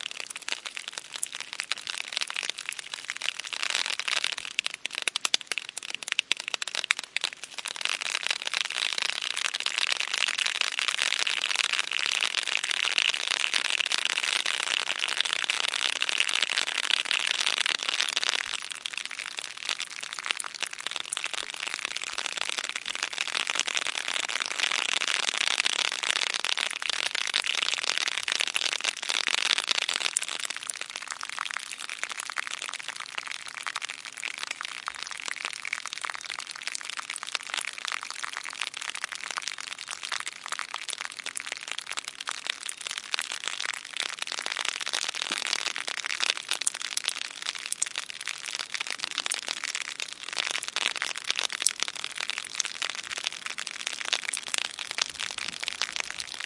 随机的 "雨滴滴落在叶子上的尖锐溪流
描述：雨涓涓细流到叶子尖锐的stream.flac
Tag: 滴流 锋利